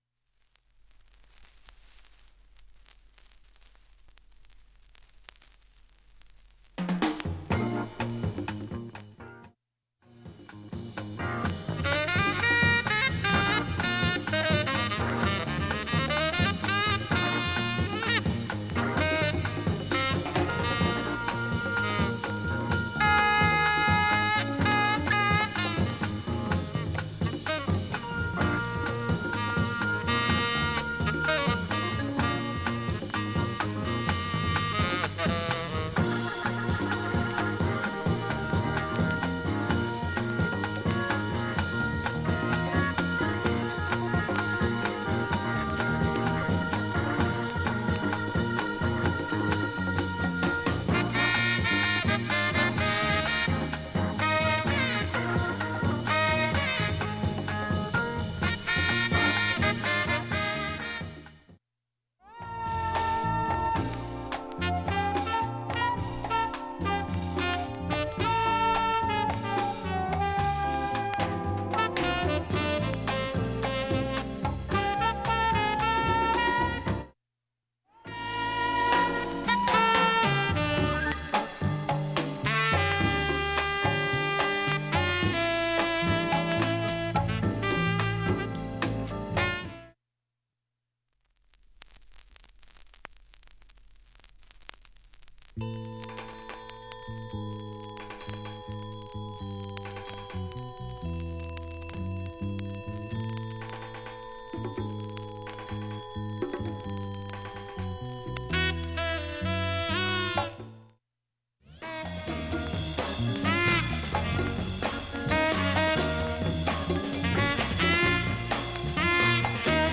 曲間軽いチリ程度
普通に聴けます音質良好全曲試聴済み。
現物の試聴（上記録音時間４分）できます。音質目安にどうぞ
３回までのかすかなプツが９箇所
単発のかすかなプツが１１箇所